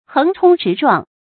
注音：ㄏㄥˊ ㄔㄨㄙ ㄓㄧˊ ㄓㄨㄤˋ
橫沖直撞的讀法